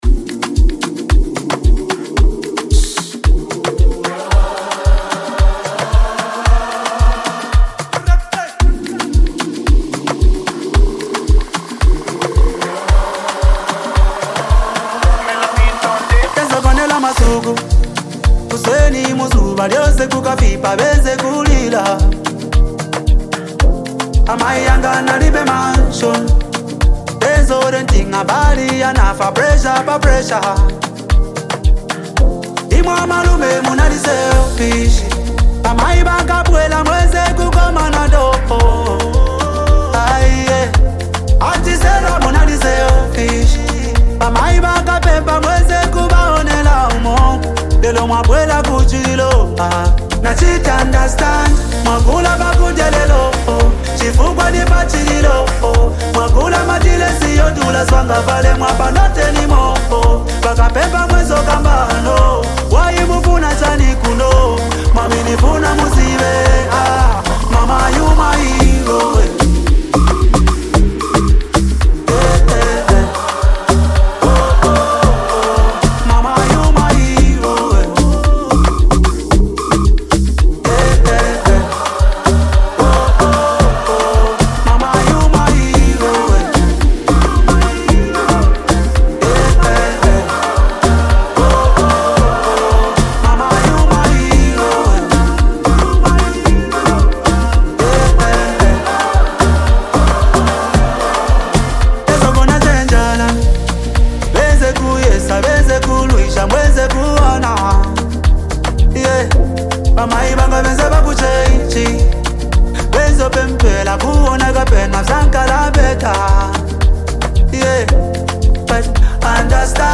blending soulful melodies with smooth instrumentals.
combined with the song’s warm and uplifting sound